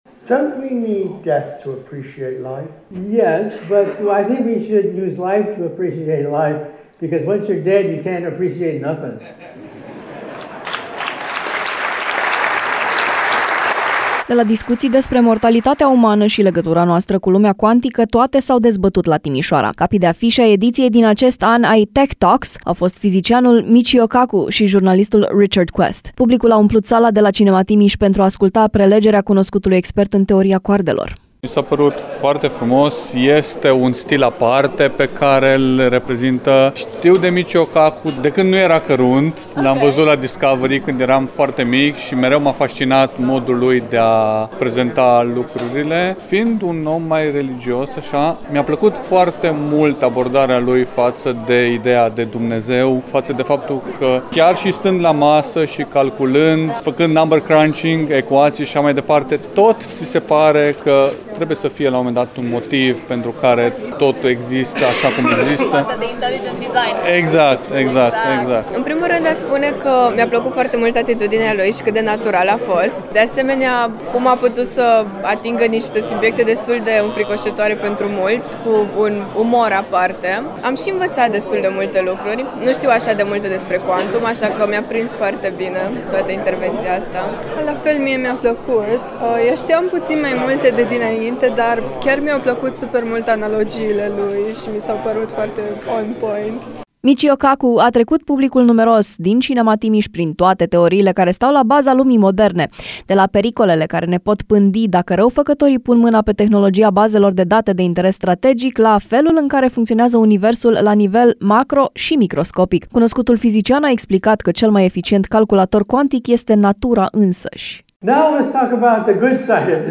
Reporter: